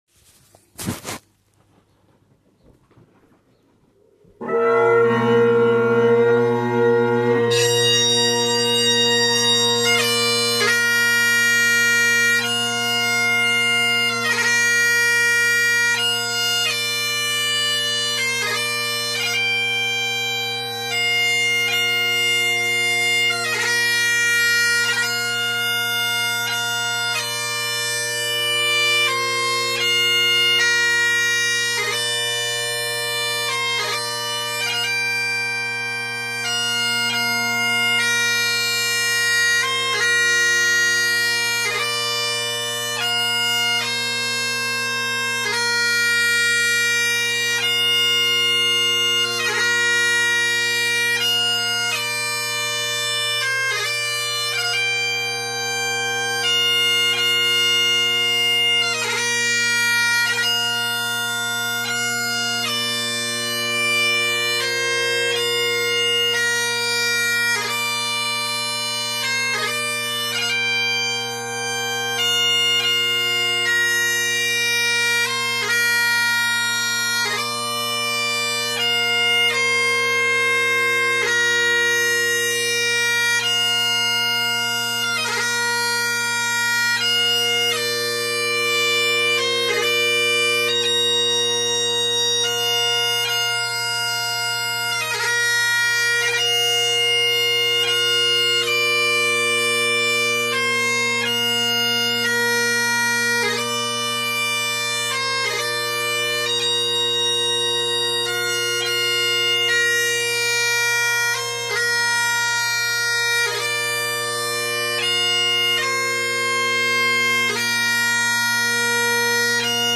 Here is a quick recording of the ground and first variation: